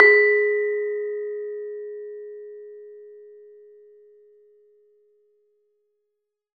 LAMEL G#3 -R.wav